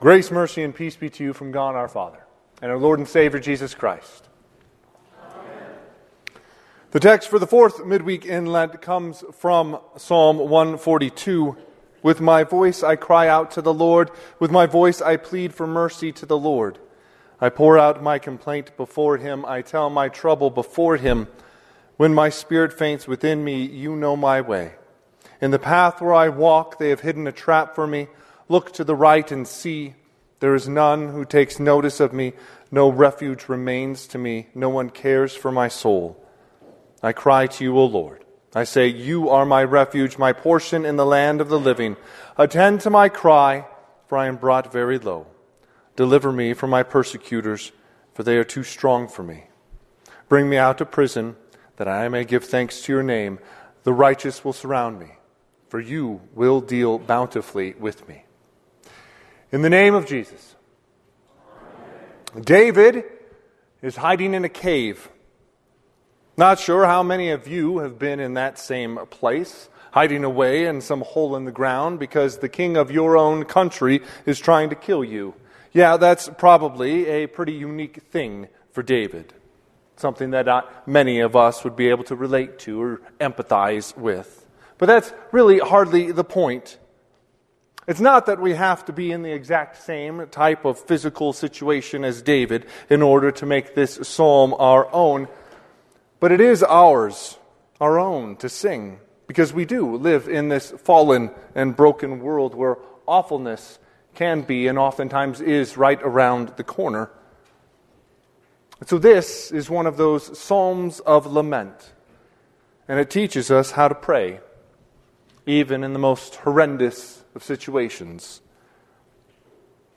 The Fourth Midweek in Lent
Sermon – 3/18/2026